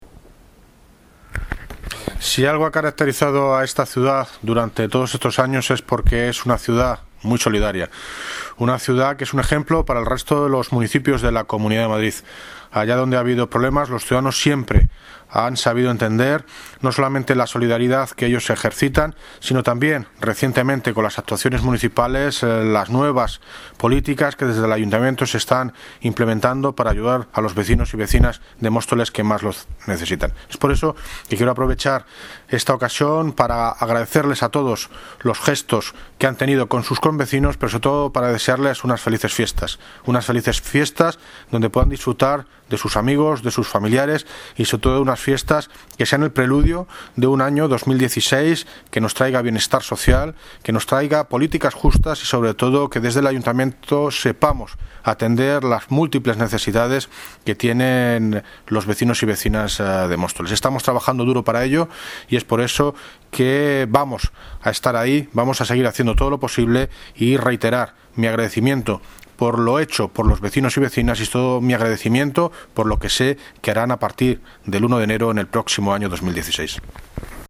David Lucas (Alcalde de Móstoles) Felicitación Navideña